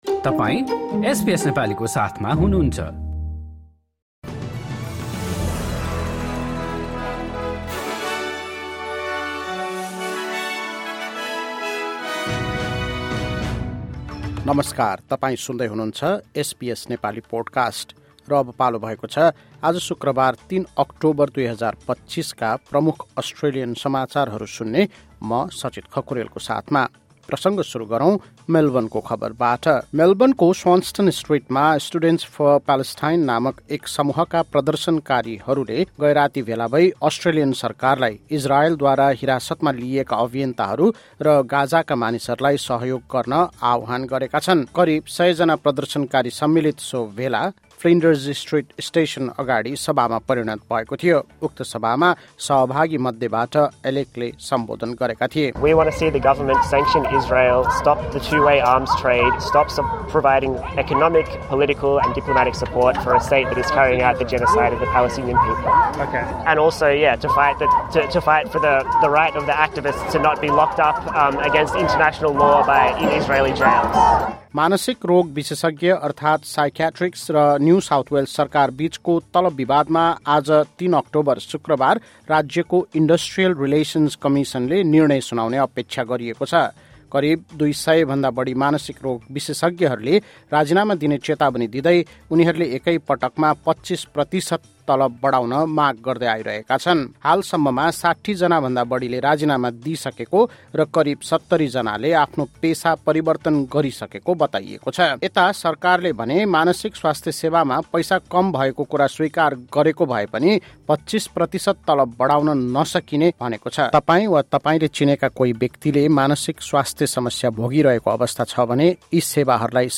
एसबीएस नेपाली प्रमुख अस्ट्रेलियन समाचार: शुक्रवार, ३ अक्टोबर २०२५